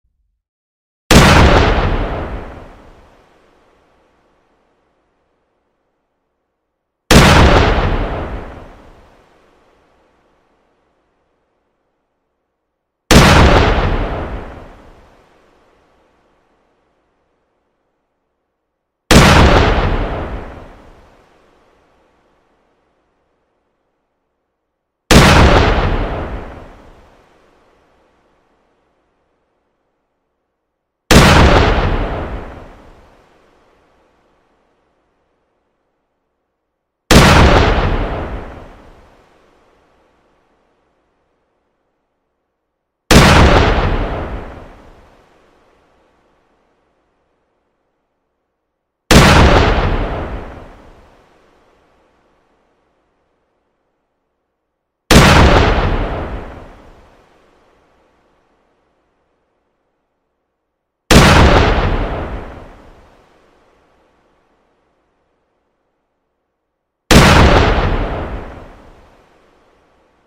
Звук выстрела из оружия, от которого вздрогнут голуби и соседи